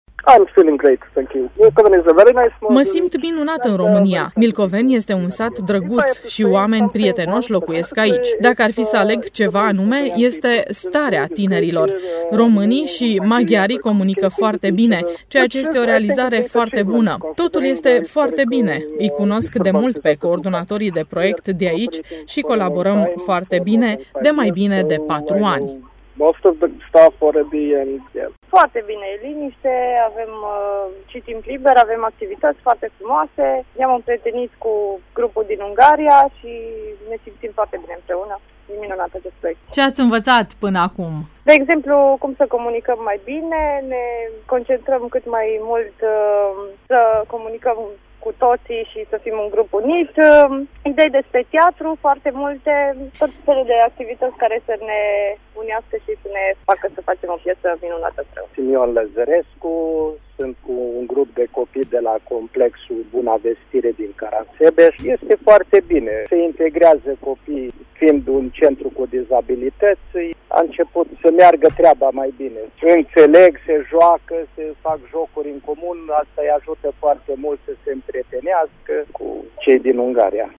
reportaj-tineri-Marabu.mp3